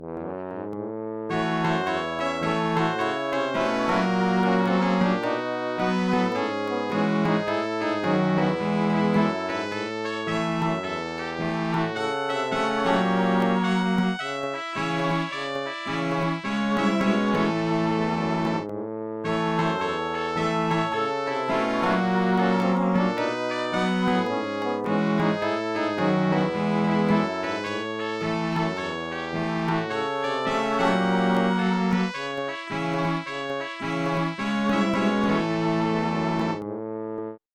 MIDI Composition
They are short and experimental.
This way my attempt at creating a walking tempo song similar to those Randy Newman had created for various motion pictures such as Toy Story and The Three Amigos.